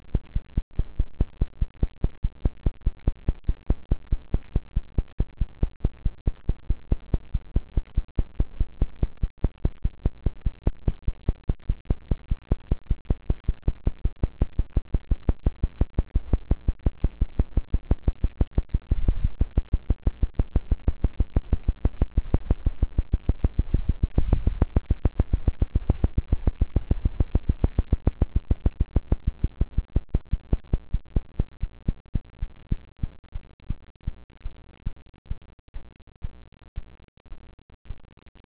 blade tip percussion wave
but it is a sound only heard close to the tips of the blades , and is inaudable from any other distance. sorry its 304Kb , but i did make it a lot smaller than it was :)
it opens with the fan on low after 10 seconds i switch the fan on medium , after 20 seconds i switch the fan on high, then i turn the fan off and let them slow down.
yes it makes perfect sense , BTW the blade sound i recorded was from my 3.5' foot turbine blade tips, the fan was just to get them spinning.
which made it sound lower in volume than the other two, because it was farther away from the mic